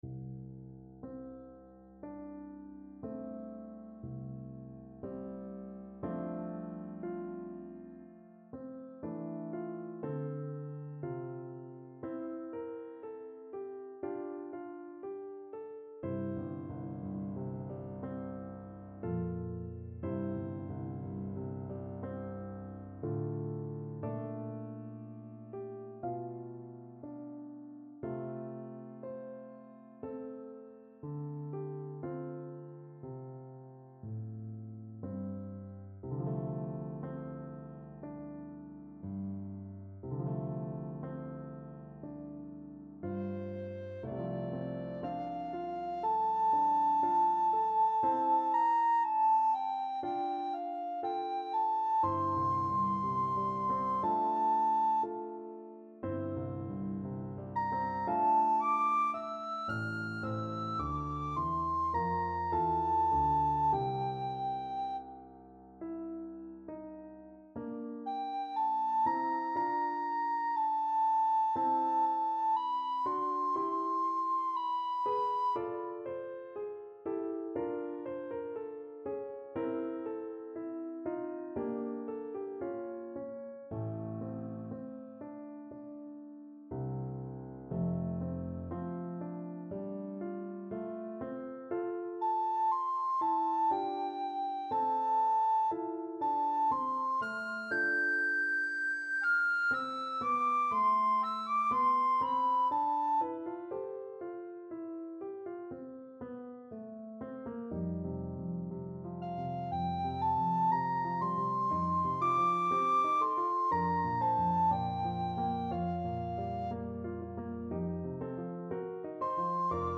Classical Mahler, Gustav Ich bin der Welt abhanden gekommen, No. 3 Ruckert-Lieder Soprano (Descant) Recorder version
Recorder
F major (Sounding Pitch) (View more F major Music for Recorder )
= 60 Molto lento e ritenuto
4/4 (View more 4/4 Music)
Classical (View more Classical Recorder Music)
Mahler_Ruckert_3_Ich_bin_REC.mp3